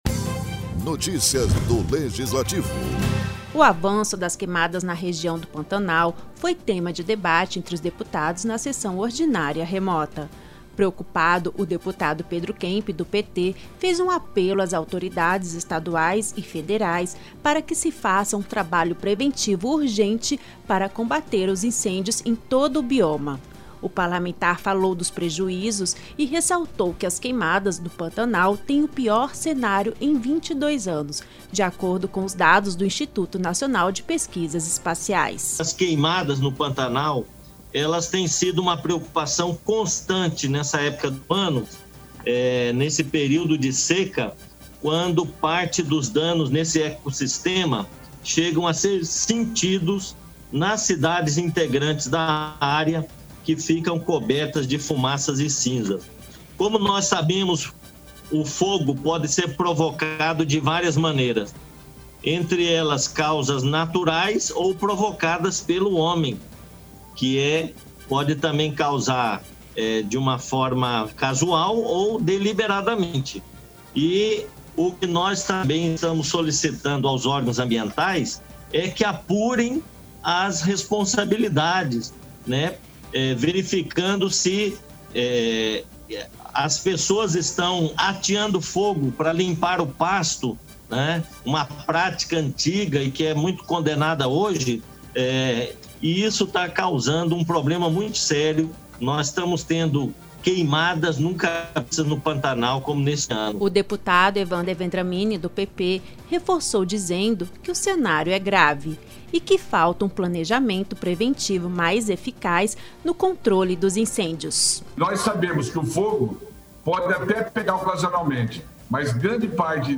O avanço das queimadas no Pantanal levaram os deputados durante a sessão ordinária remota desta terça-feira (8), a pedirem providências às autoridades responsáveis para que se façam uma trabalho mais rígido no controle e fiscalização dos incêndios no Bioma.